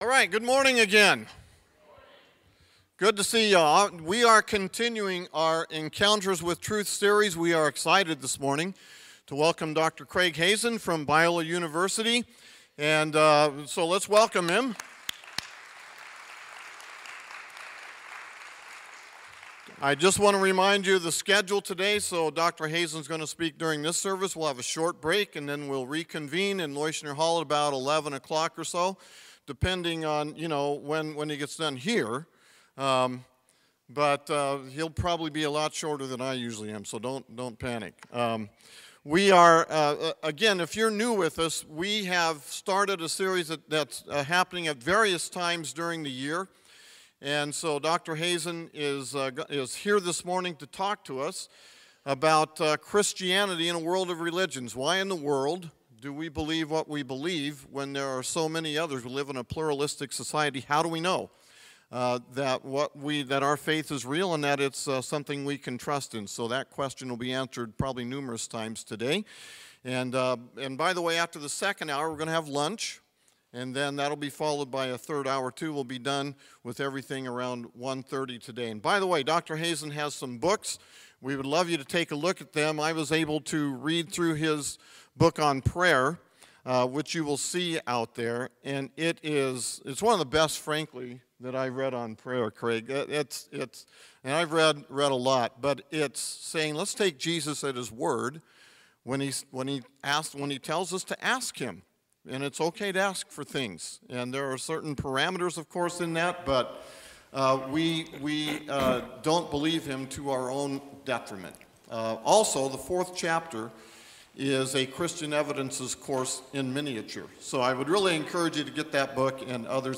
Sermons | Magnolia Baptist Church